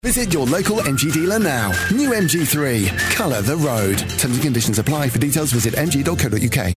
Automobil
Ich habe zu Hause ein eigenes Aufnahmestudio, in dem ich an allen möglichen Audioprojekten arbeite.
Mein freundlicher und bodenständiger Ton, der zwischen den tiefen und mittleren Tönen liegt, verbindet das Publikum auf eine Art und Weise, die sowohl fesselnd als auch zugänglich ist.
BaritonBassTiefNiedrig